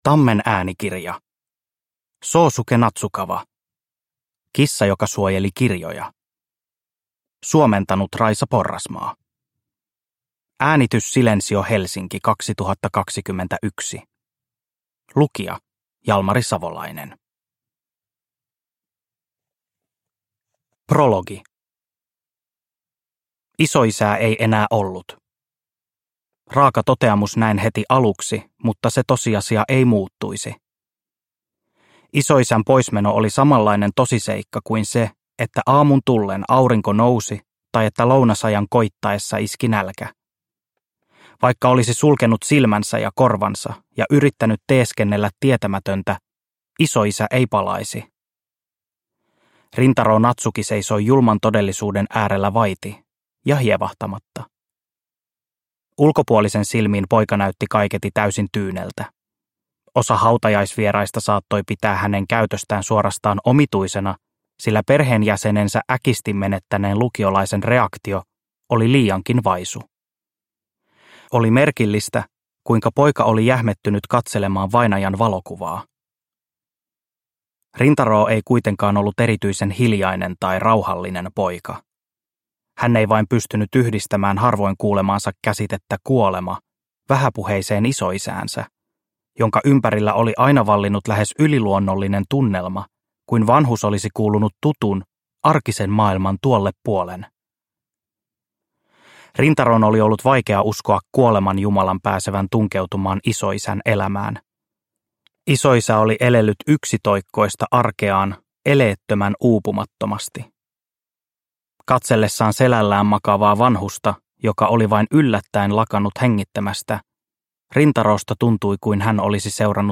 Kissa joka suojeli kirjoja – Ljudbok – Laddas ner